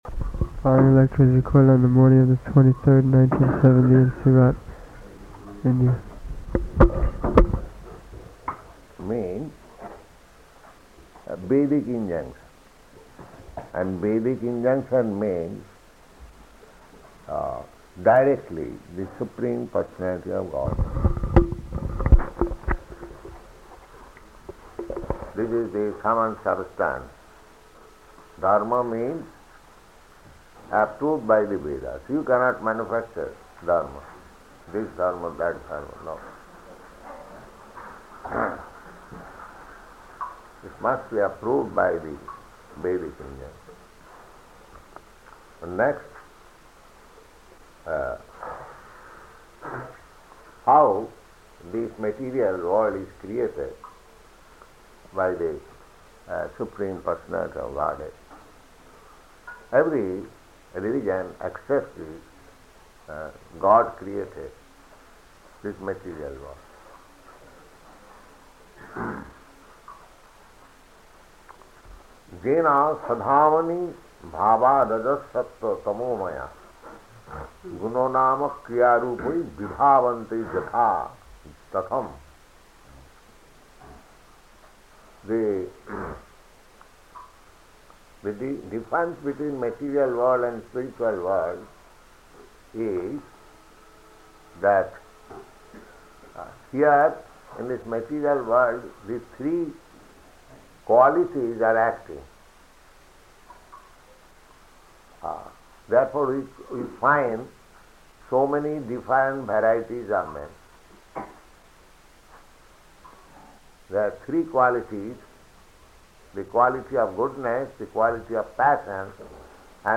Type: Srimad-Bhagavatam
Location: Surat